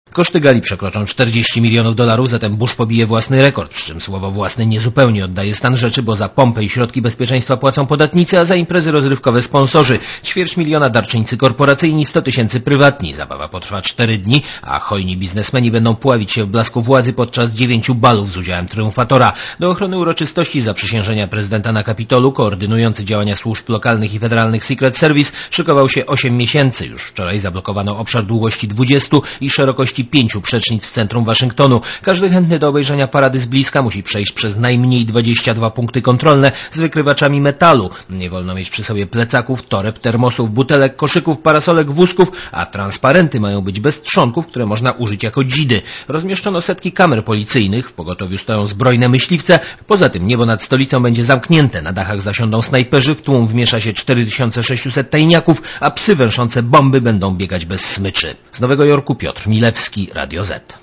Relacja reportera Radia Zet Telewizja BBC umieściła w środę na swoich stronach internetowych ankietę o światowym bezpieczeństwie po ponownym wyborze Bush’a na prezydenta USA.